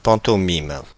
Ääntäminen
Synonyymit mime Ääntäminen France (Paris): IPA: /pɑ̃.tɔ.mim/ Haettu sana löytyi näillä lähdekielillä: ranska Käännöksiä ei löytynyt valitulle kohdekielelle.